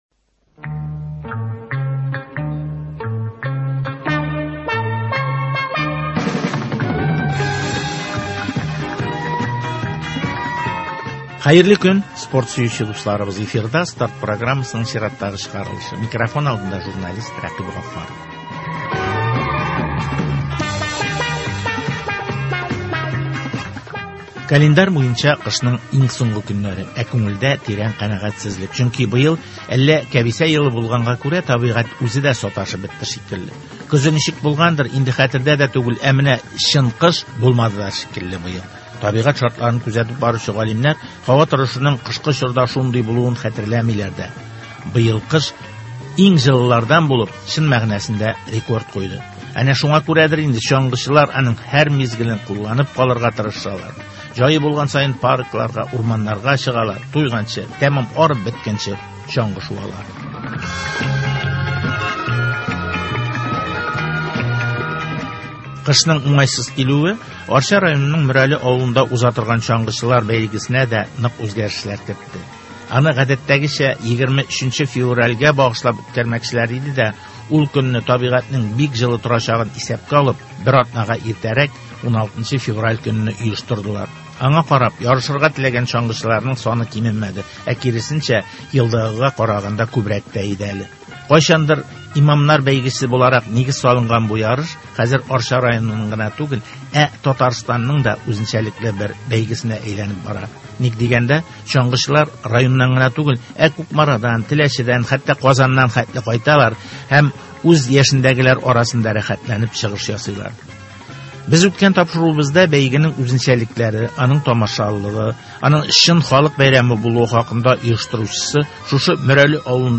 Арча районының Мөрәле авылында узган чаңгы ярышлары, аның халыкны тәрбияләүдә һәм берләштерүдәге роле хакында репортаж.